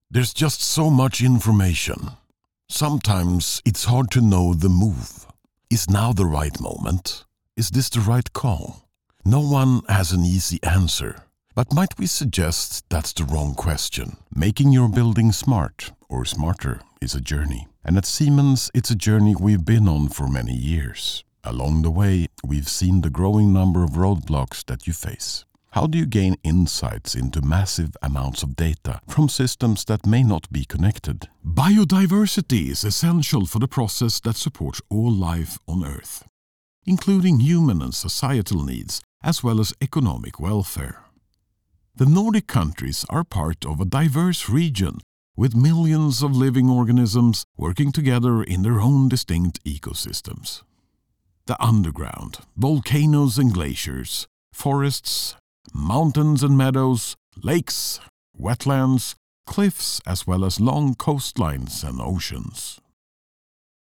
Male
Approachable, Authoritative, Confident, Conversational, Corporate, Deep, Energetic, Engaging, Versatile, Warm
European english with a slight swedish twang
Microphone: Austrian Audio OC18